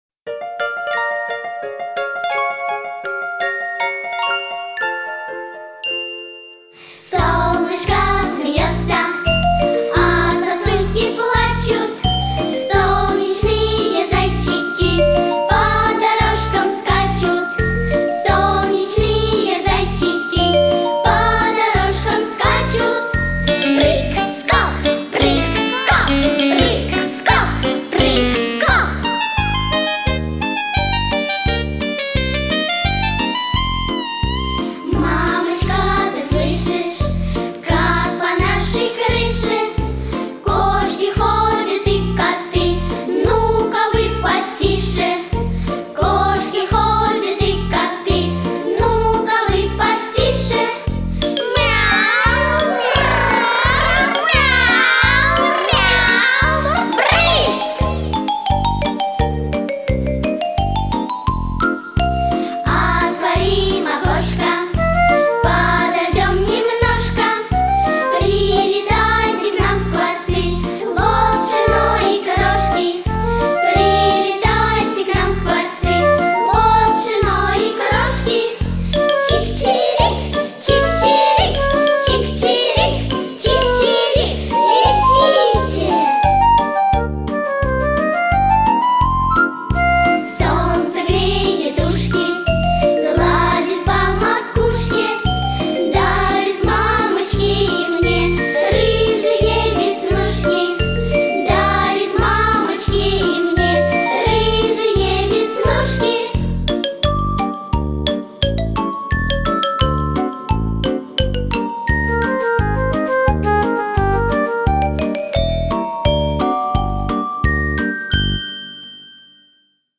Детские песни и музыка